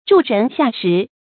助人下石 注音： ㄓㄨˋ ㄖㄣˊ ㄒㄧㄚˋ ㄕㄧˊ 讀音讀法： 意思解釋： 幫助別人去作害人的事。